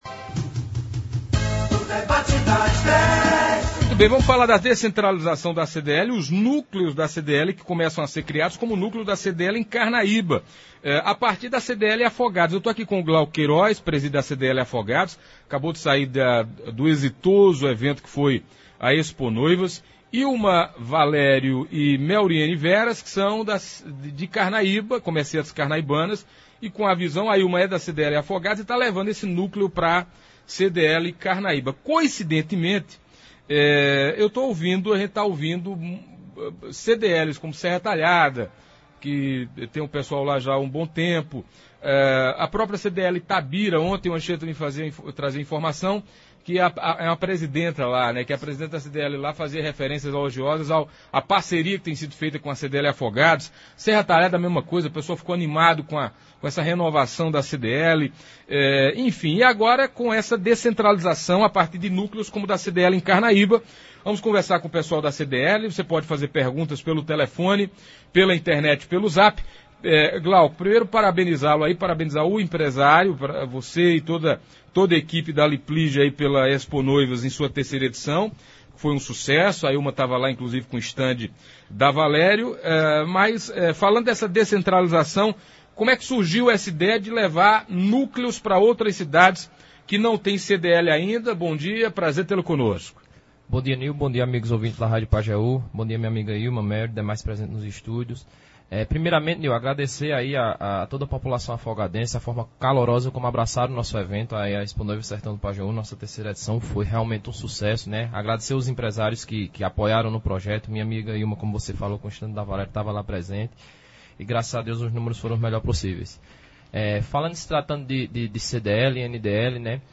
Ouça abaixo na íntegra como foi o debate de hoje: